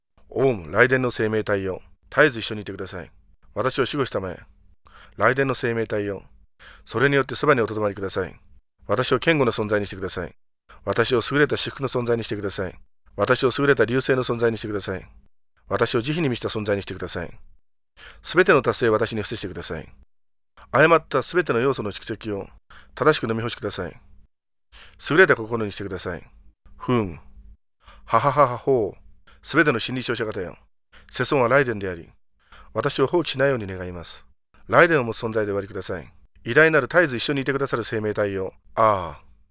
Стослоговая мантра Ваджрасаттвы (mp3 1,51 Mb. 0:39). Начитывание для практики, японское прочтение.